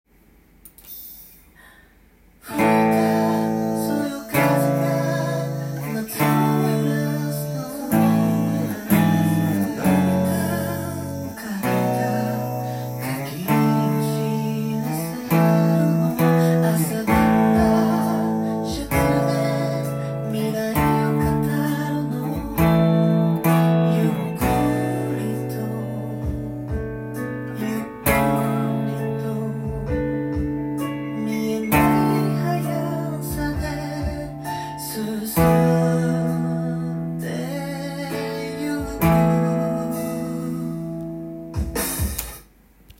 音源にあわせて譜面通り弾いてみました
keyがAになるのでAのダイアトニックコードで構成されていますが
パワーコードで弾けるように譜面に記載していますが